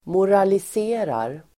Uttal: [moralis'e:rar]